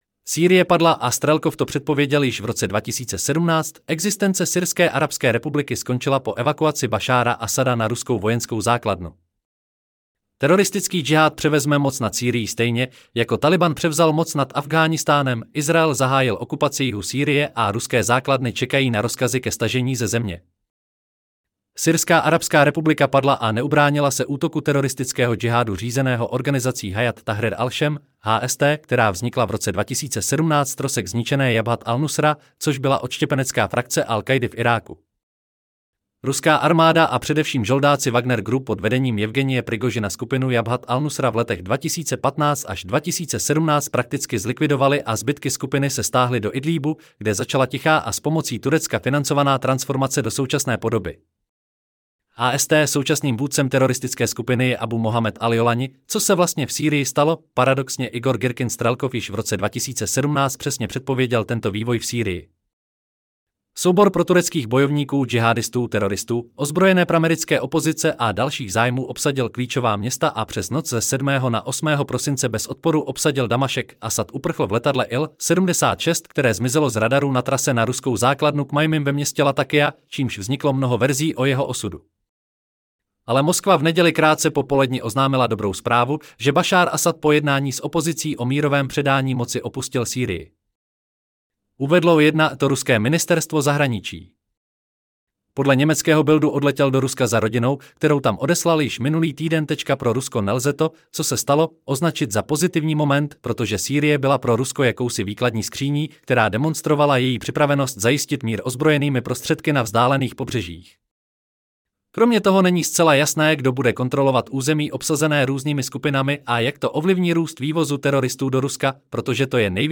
Celý článek si můžete poslechnout v audioverzi zde: Syrie-padlaA-Strelkov-to-predpovedel-jiz-v-roce-2017Existence-Syrske-arabske-republiky-skoncila 8.12.2024 Sýrie padla!